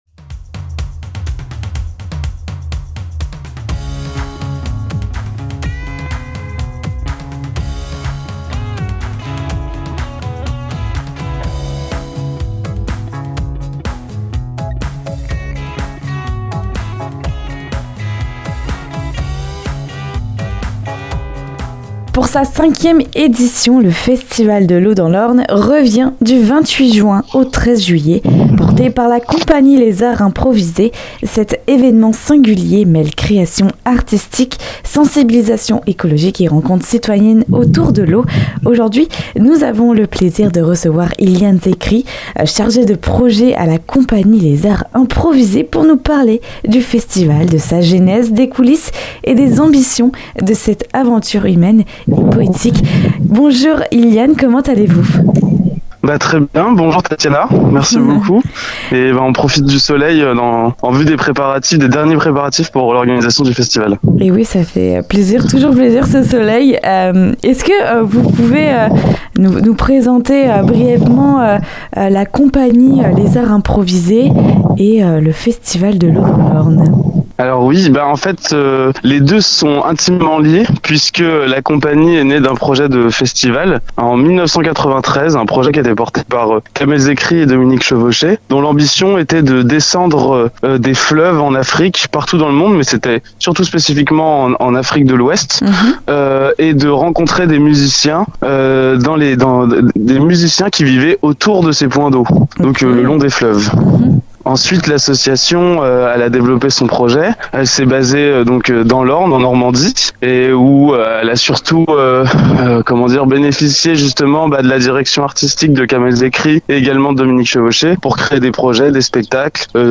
Radio Pulse 90.0FM à Alençon
Il nous embarque dans la riche programmation qui vous attends du 28 juin au 13 juillet prochain. Avec au rendez-vous de la musique, du spectacle, des apéros surprises... ET pour finir en beauté, un magnifique feu d'artifice ! Pour en savoir plus sur cet rencontre n'hésitez pas à écouter jusqu'au bout l'interview et à partir à la découverte de ce festival !